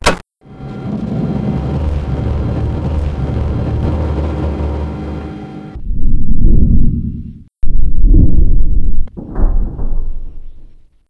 gear.wav